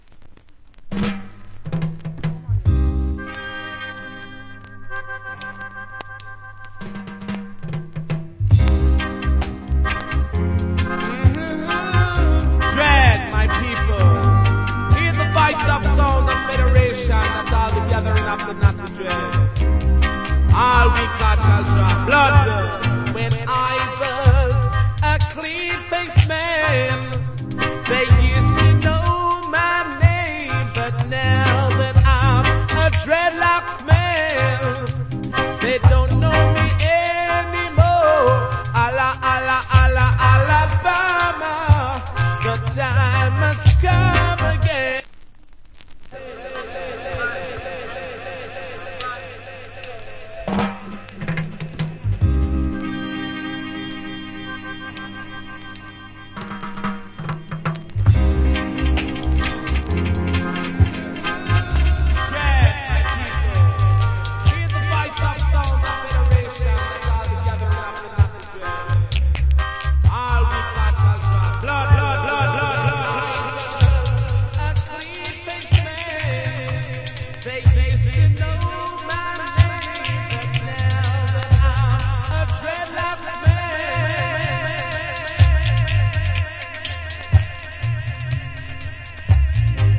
Cool Roots Rock Vocal . Nice Dub.